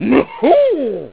Mario Kart DS Sounds